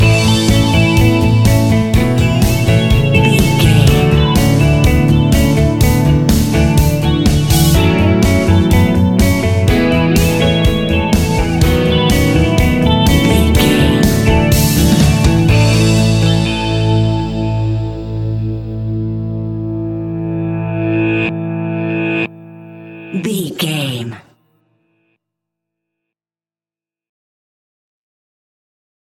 Uplifting
Ionian/Major
pop rock
indie pop
fun
energetic
cheesy
instrumentals
guitars
bass
drums
piano
organ